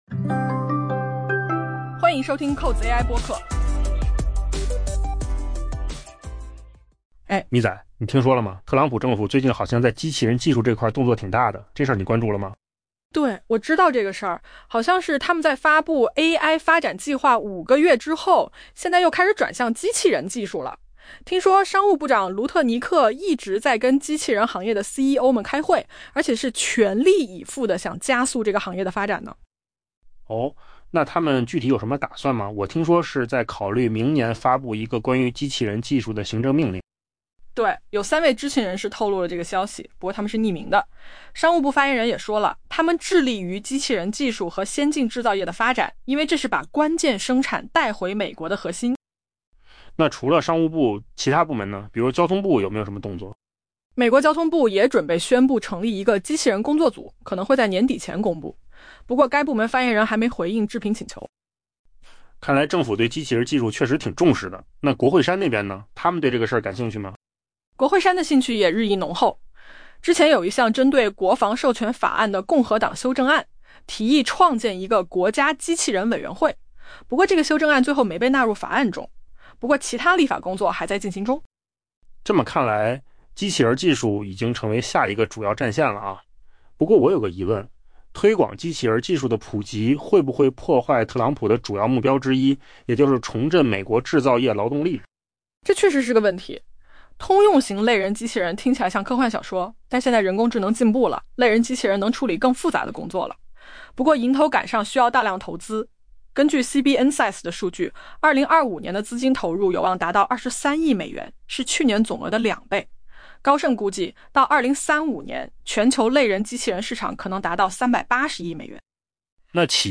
AI 播客：换个方式听新闻 下载 mp3 音频由扣子空间生成 在发布加速人工智能 （AI） 发展计划五个月后，特朗普政府正在转向机器人技术。